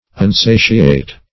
unsatiate - definition of unsatiate - synonyms, pronunciation, spelling from Free Dictionary
unsatiate - definition of unsatiate - synonyms, pronunciation, spelling from Free Dictionary Search Result for " unsatiate" : The Collaborative International Dictionary of English v.0.48: Unsatiate \Un*sa"ti*ate\, a. Insatiate.